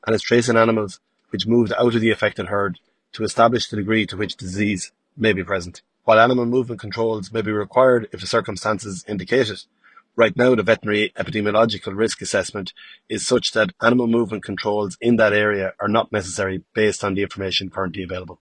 Agriculture Minister Martin Heydon says surveillance is ongoing…………….